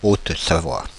Haute-Savoie (pronounced [ot savwa]
Fr-Paris--Haute-Savoie.ogg.mp3